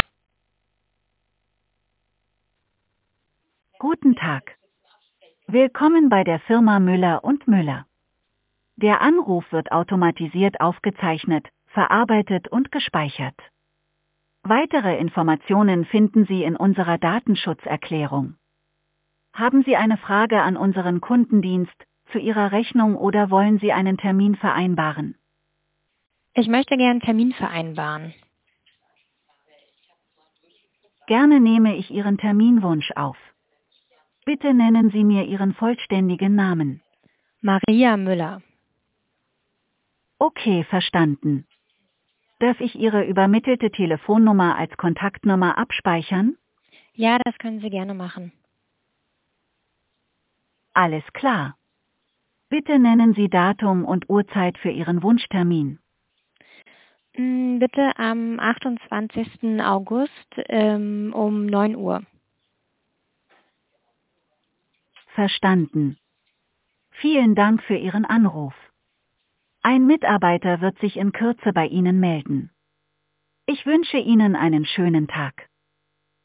Der intelligente 11880-Sprachbot unterstützt Ihren Handwerksbetrieb rund um die Uhr, an 365 Tagen im Jahr.